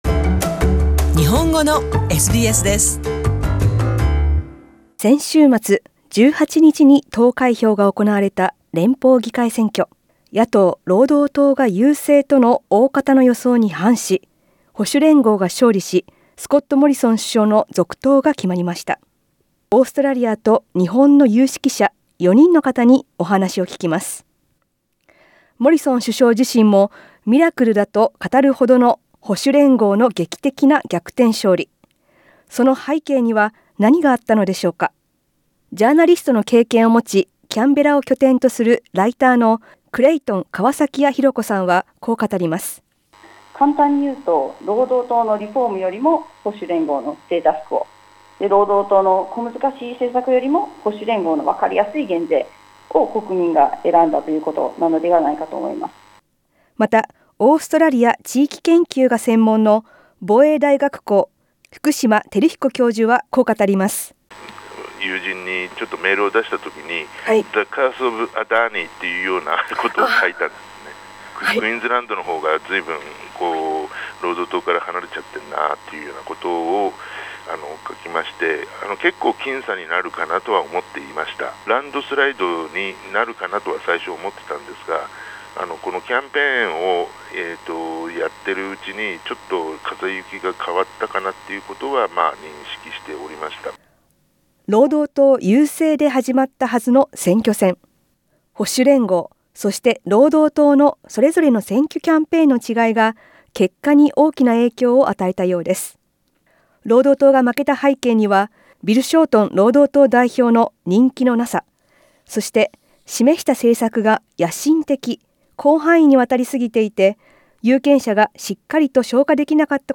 Four Japanese experts share their insights on what has happened. Broadcast on the 21st of May, 2019.